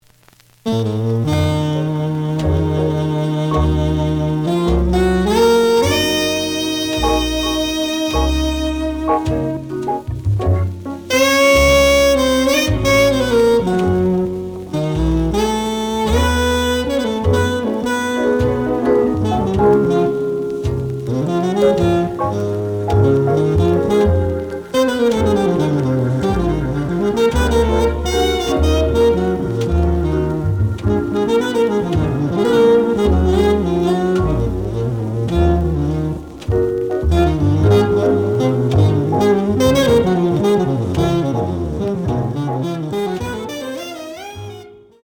The audio sample is recorded from the actual item.
●Genre: Cool Jazz
Slight edge warp.